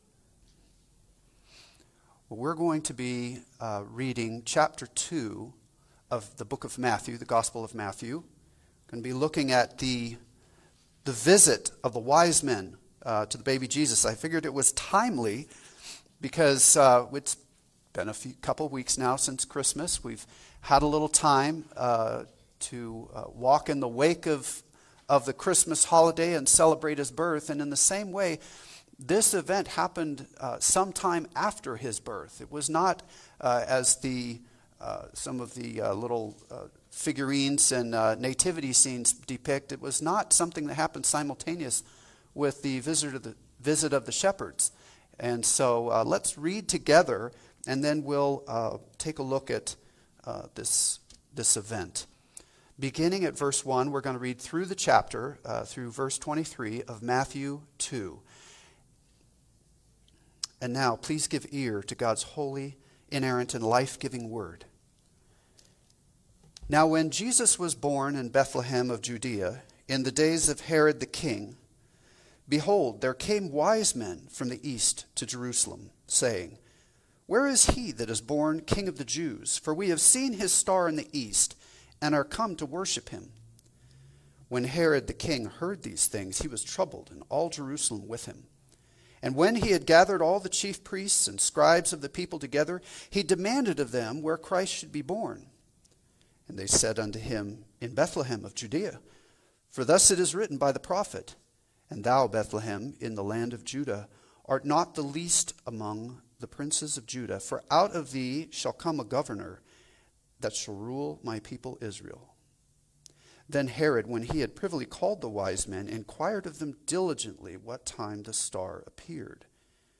Passage: Matthew 2:1-23 Service Type: Sunday Service